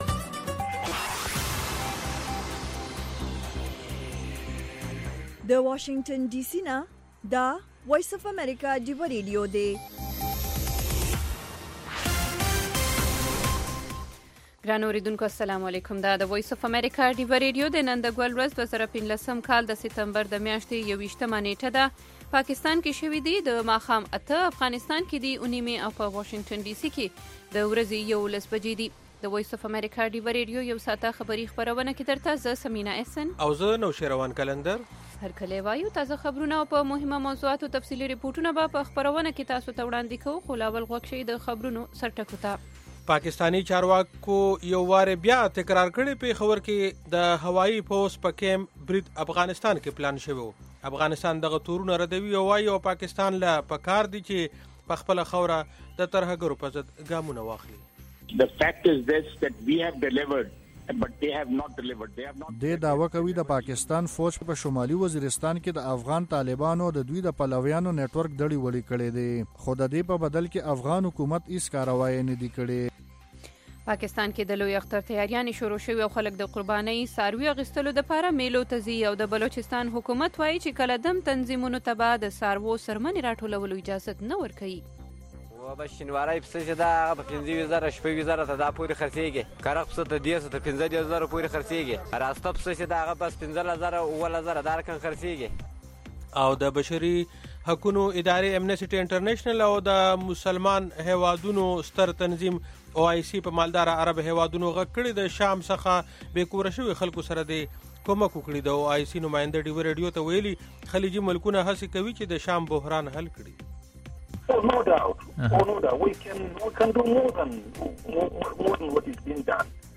خبرونه